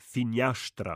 /ʃ/ before c, m, n, p, t, tg